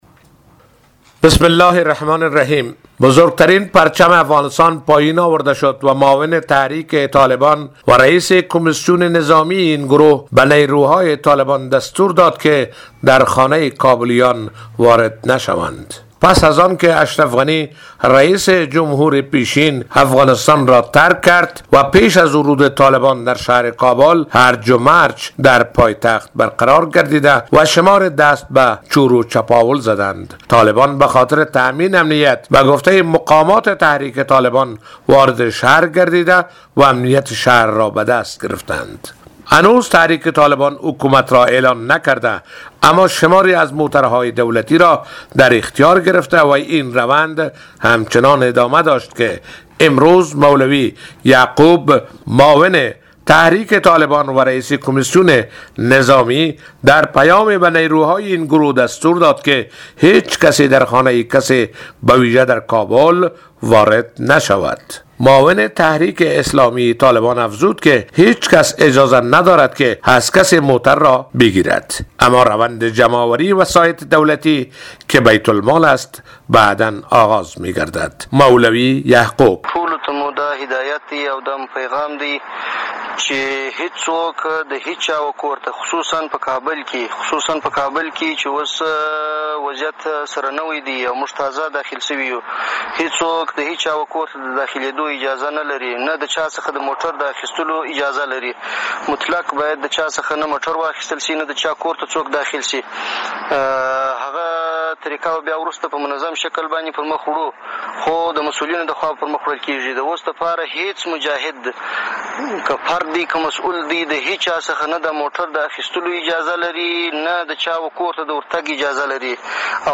طالبان بزرگترین پرچم افغانستان از تپه وزیر محمد اکبر خان را پایین کشید . گزارش آخرین اقدامات طالبان در کابل از خبرنگار رادیو دری :